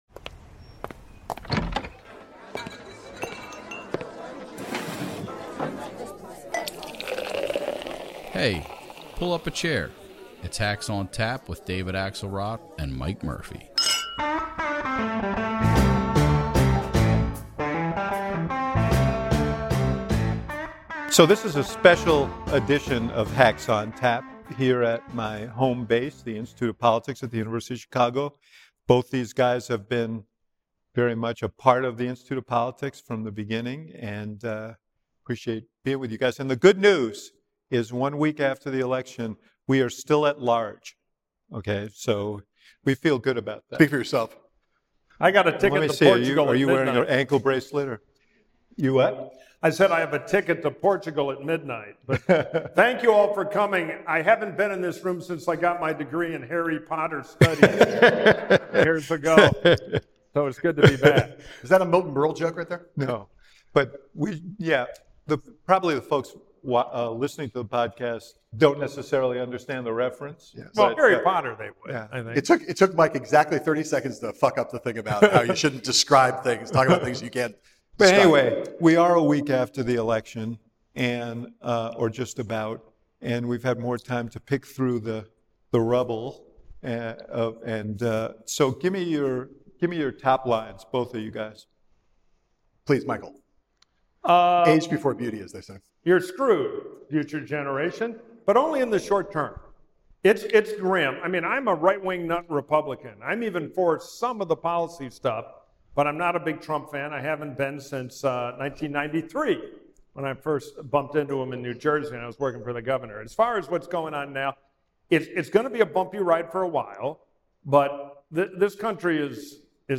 Headliner Embed Embed code See more options Share Facebook X Subscribe This week, the Hacks report from the esteemed Institute of Politics at the University of Chicago. They sift through the aftermath of the election to analyze the strategies of both the Trump and Harris campaigns, Trump’s proposed appointments, the battle for leadership, and what these developments mean for the next four years and beyond.